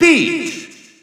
French announcer announcing Peach's name.
Peach_French_Announcer_SSBU.wav